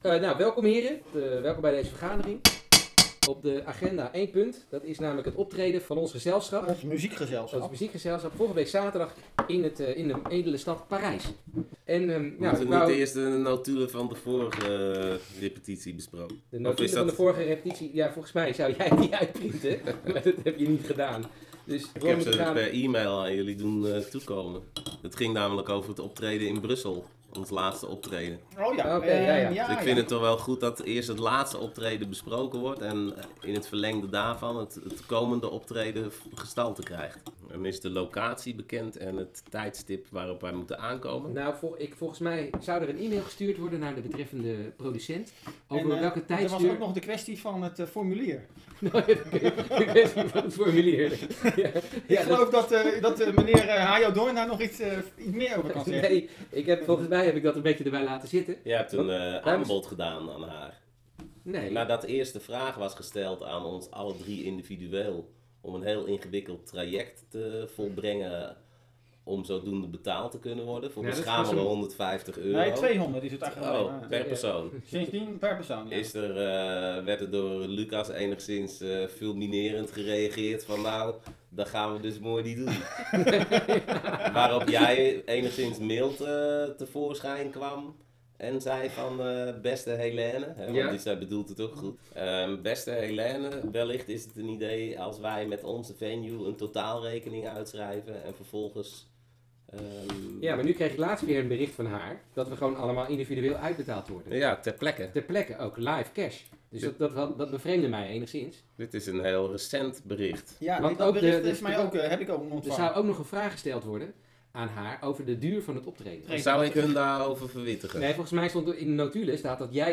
coolhaven-hoorspel-radiovoicemail.wav